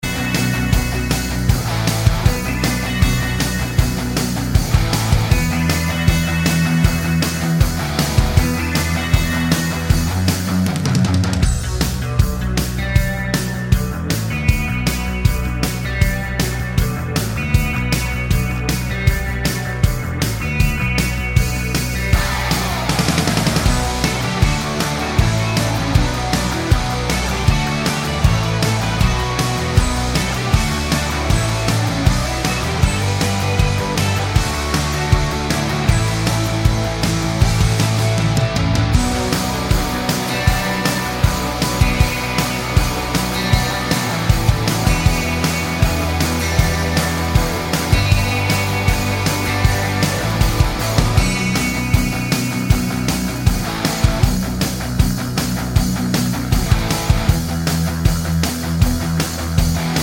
no Backing Vocals Finnish 4:01 Buy £1.50